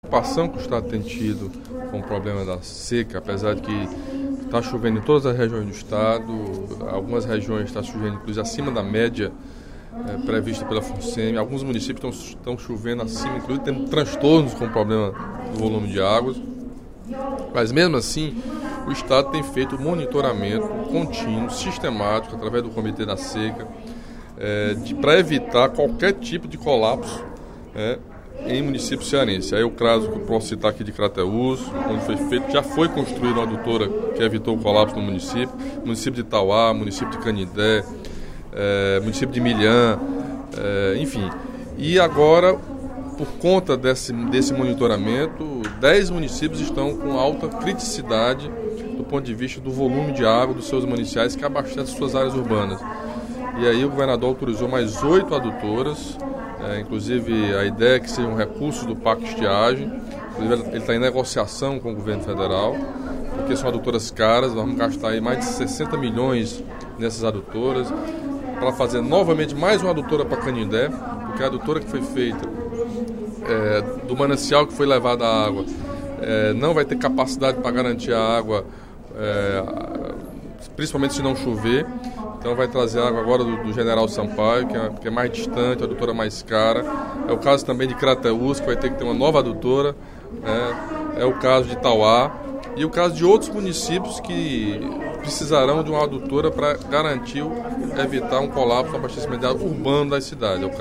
Em pronunciamento durante o primeiro expediente da sessão plenária desta terça-feira (18/02), o deputado Camilo Santana (PT) anunciou que o Governo do Estado está em negociação com o Governo Federal para viabilizar a construção de oito novas adutoras emergenciais no Ceará, com recursos do PAC Estiagem.